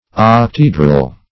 Octaedral \Oc`ta*e"dral\, a.